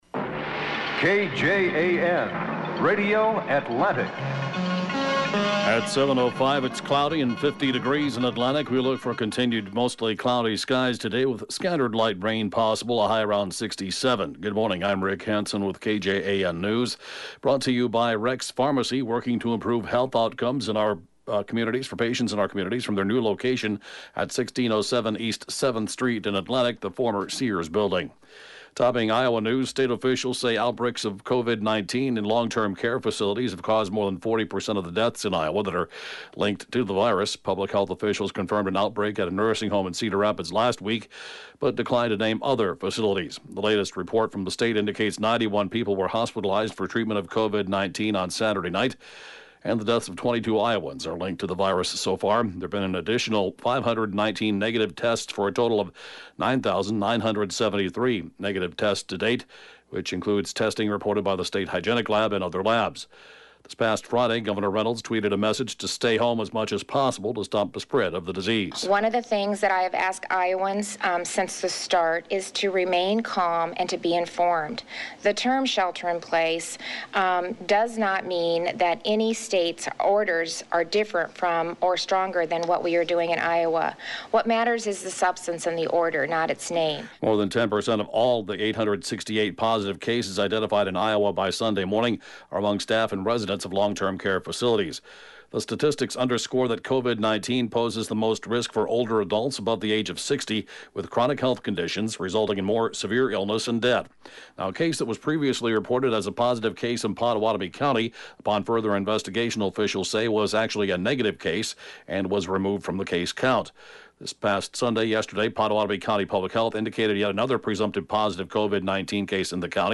(Podcast) KJAN Morning News & Funeral report, 4/6/20